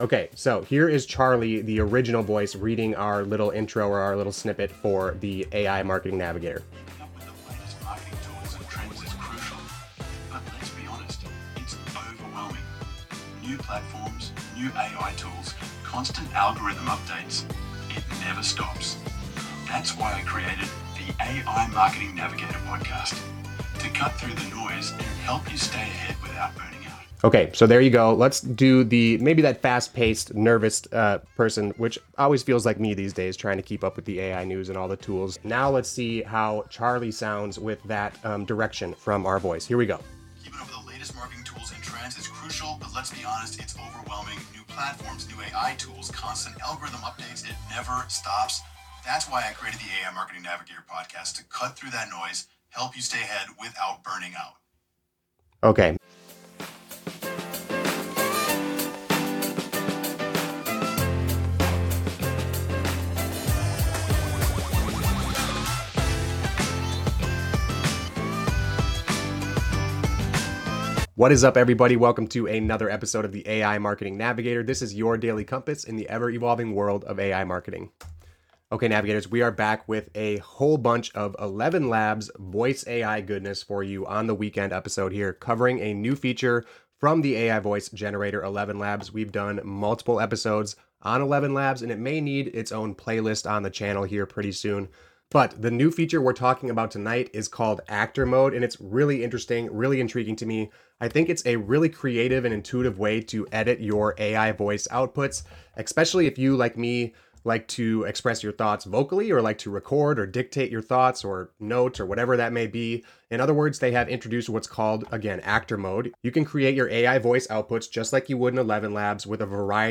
In this episode, we explore ElevenLabs' new Actor Mode feature, which enables users to direct AI-generated voices using their own vocal performance patterns. This innovative approach allows for precise control over pacing, emphasis, and emotional inflection in AI voice outputs - a significant advancement for creating more natural-sounding content.